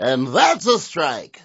wack_andthatsastrike.wav